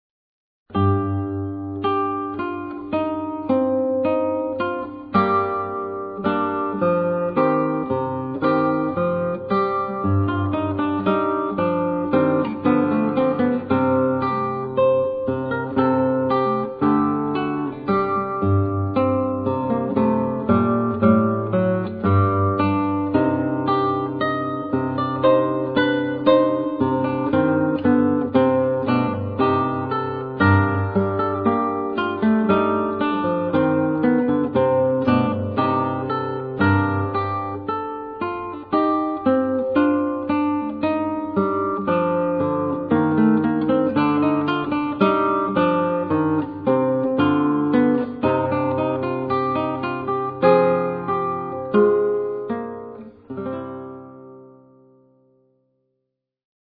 Classical
Solo guitar